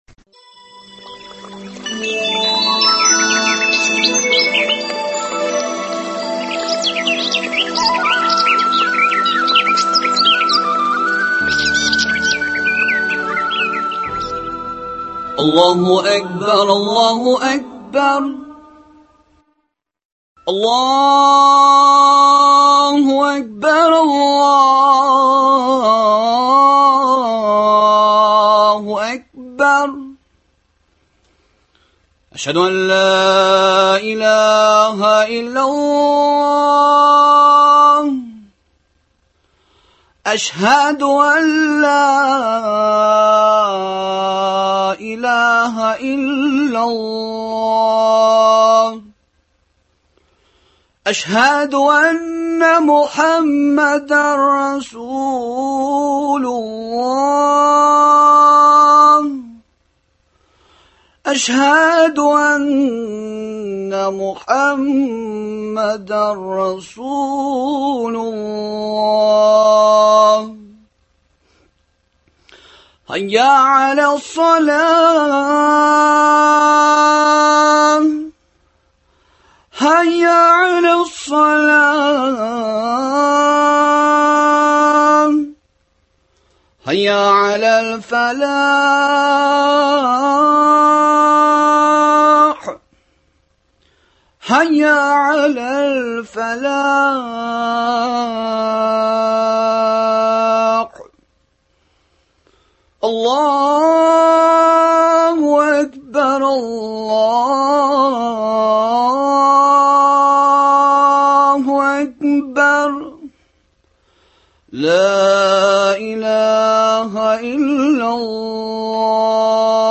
Иртәнге эфирда — дини темаларга әңгәмәләр.